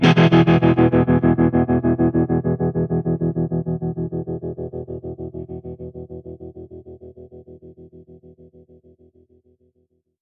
Trem Trance Guitar Ending.wav